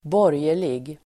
Uttal: [²b'år:jer_lig]